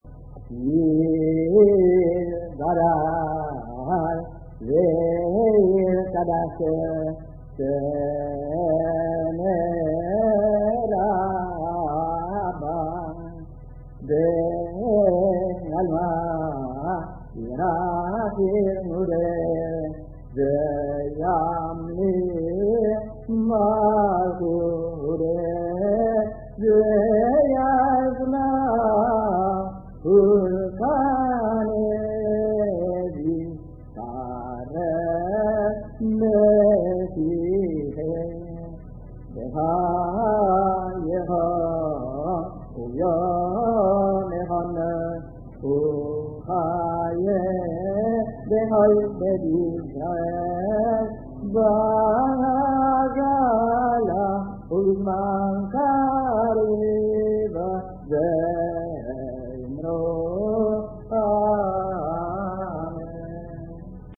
Altre registrazioni storiche